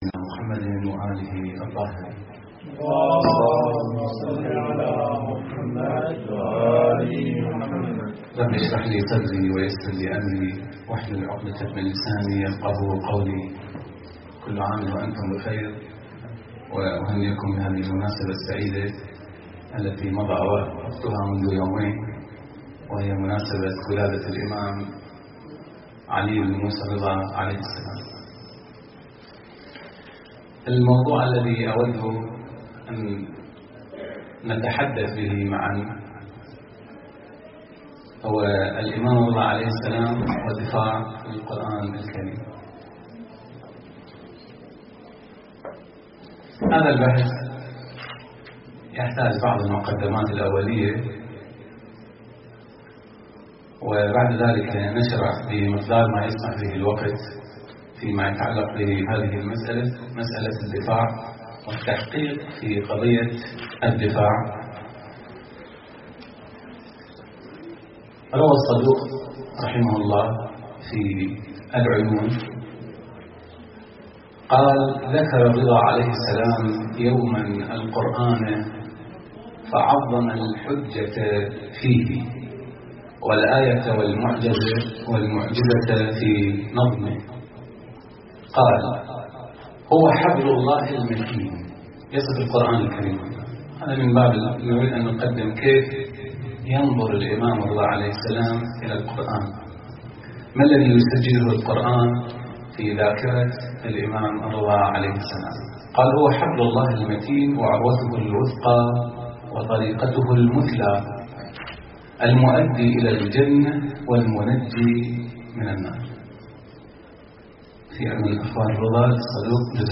محاضرة ( الإمام الرضا (ع) والدفاع عن القرآن ) - لحفظ الملف في مجلد خاص اضغط بالزر الأيمن هنا ثم اختر (حفظ الهدف باسم - Save Target As) واختر المكان المناسب